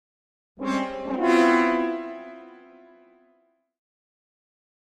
Horn Fanfare Victory Signal - Derisive Version 2